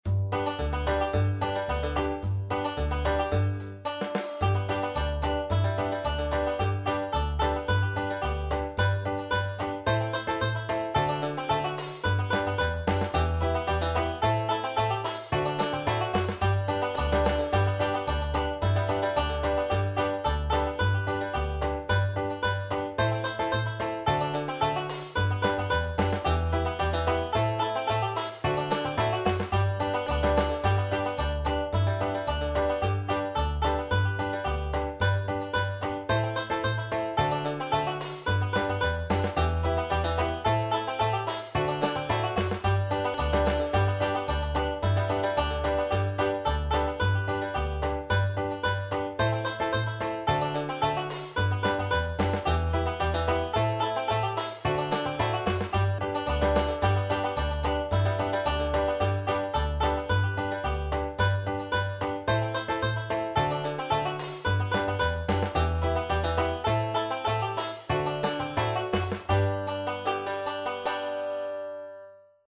banjo.mp3